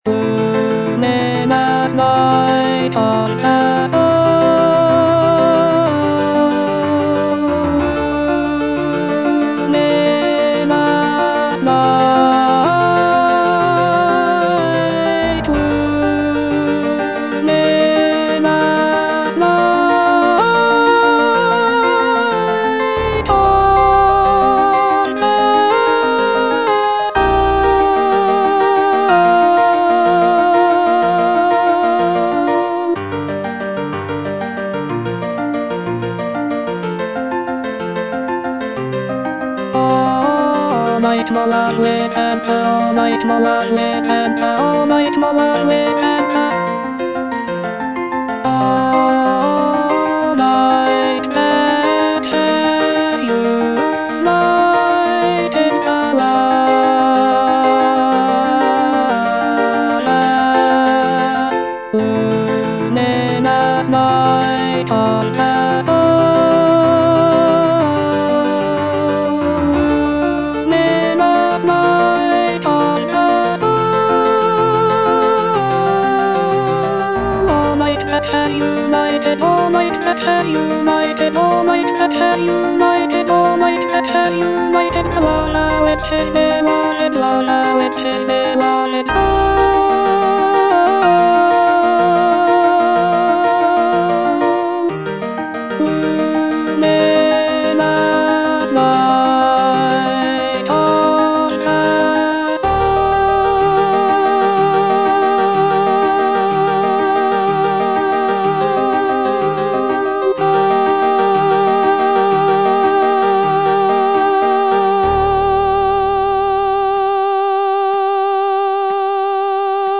(alto II) (Voice with metronome) Ads stop
choral work